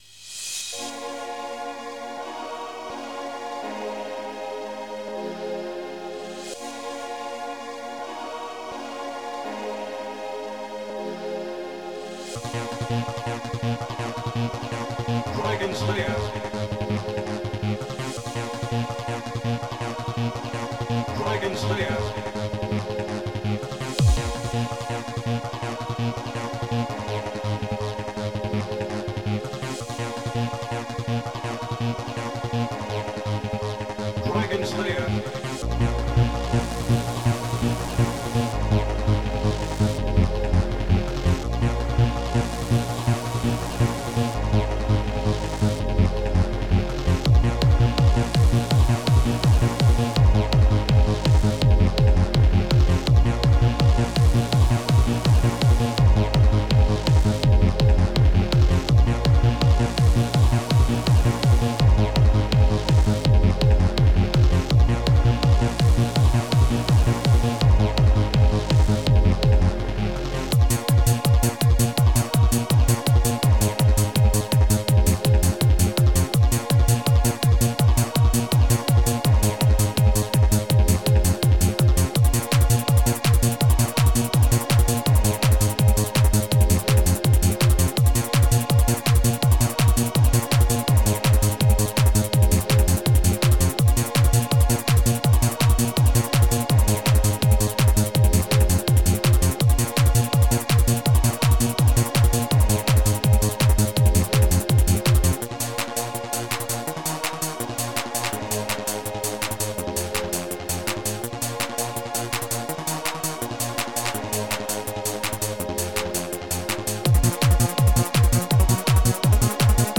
Protracker Module
___----trance---